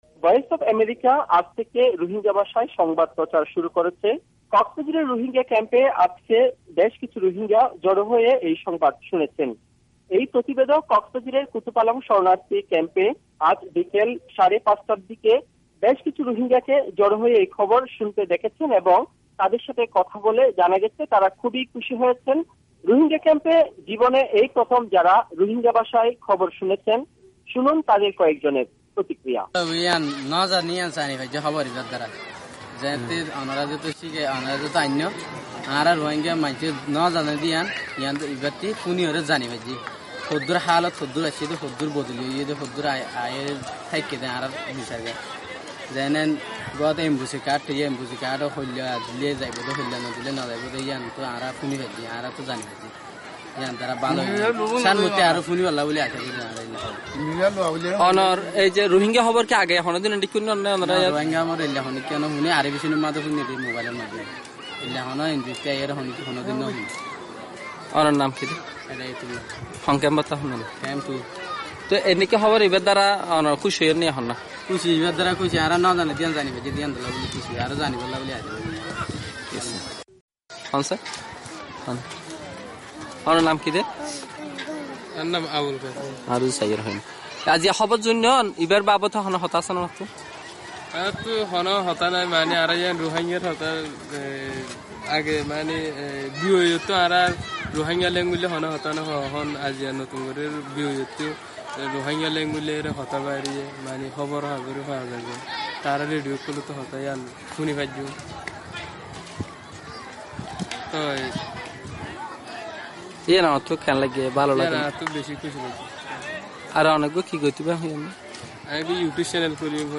বেশ কয়েকজন রোহিঙ্গা স্রোতার সঙ্গে কথা বলেছেন।